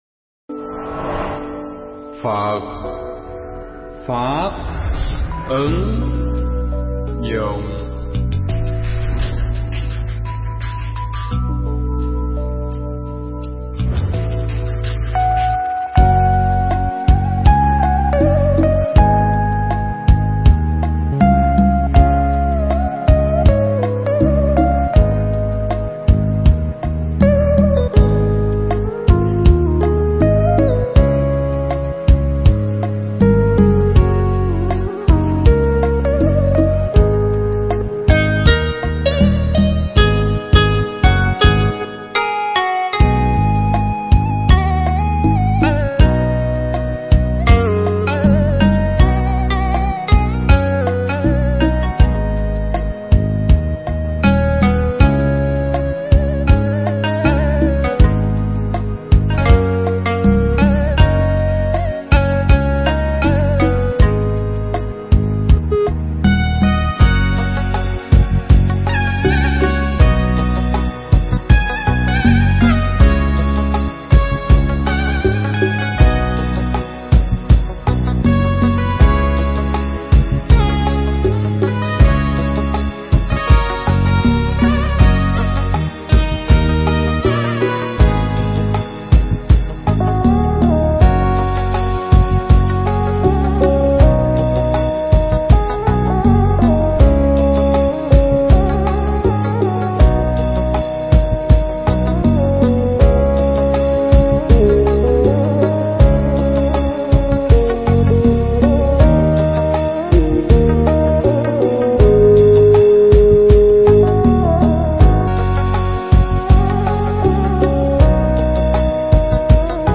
Thuyết pháp Ra Khỏi Rừng Mê